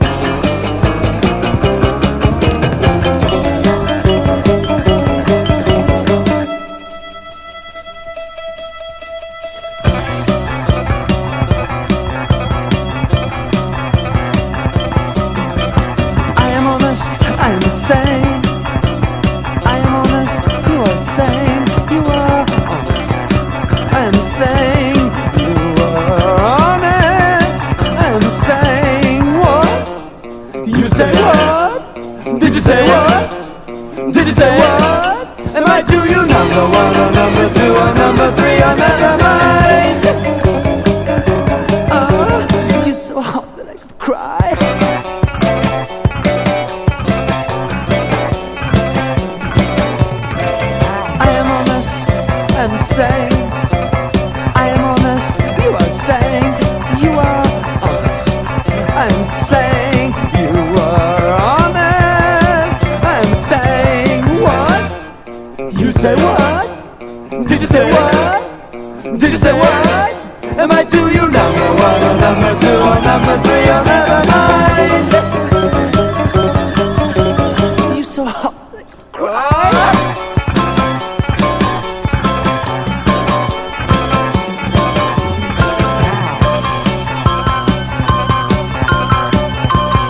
ROCK / 70'S / GUITAR / SWAMP / BLUES ROCK